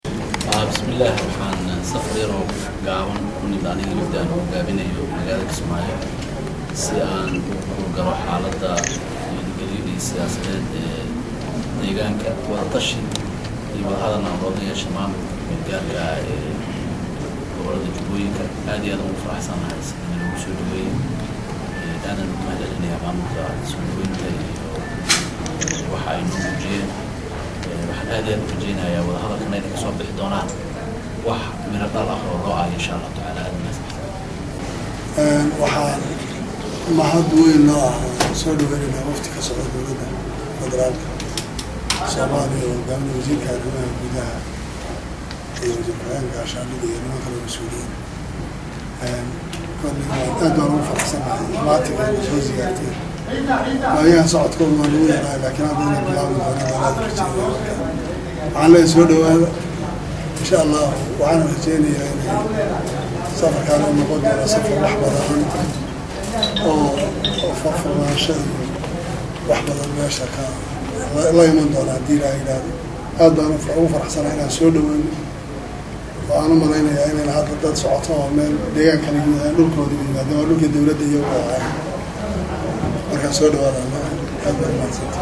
Wasiradan oo u soo dhaadhacay dhanka madaxtooyada magalda kismaayoo halkaasi oo ay kula kulmeyn madaxweynaha maamulka KMG ee Jubba Axmed Max’ed Islaam ,sidoo kale waxaa halakaas hadal kooban ka jeediyaya madaxweynaha maamulka Jubba iyo Wasiirka  Arimaha Gudaha Cabdikariin xuseen oo sheegay in ay u kuurgali  doonaan xalada amniga iyo arimo badan ooa kamidtahay wadahadaladii u socaday dowlada soomaaliya iyo maamulka KMG ee Jubba.